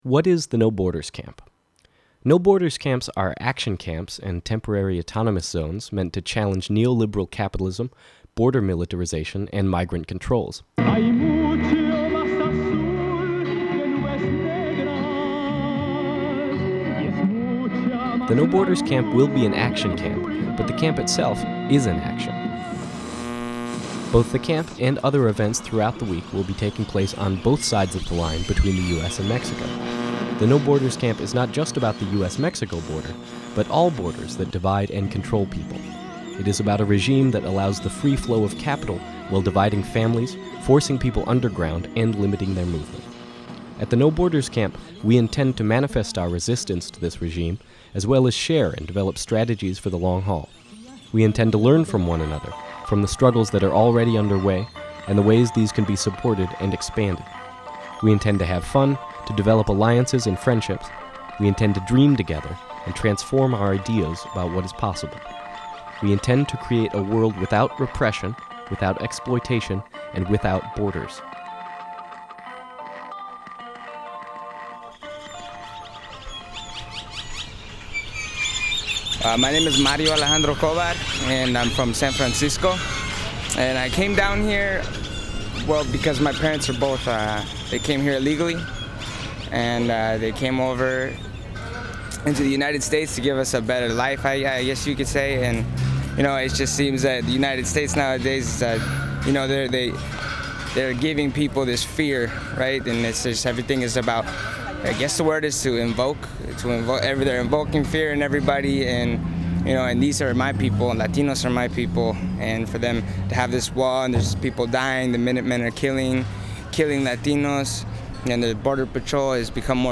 This 5min9sec radio doc gives a quick introduction to the ideas behind the No Borders Camp, the purpose of the No Borders Camp, and then takes a sound walk through the border at Calexico
tuesday_border_walk_mp3.mp3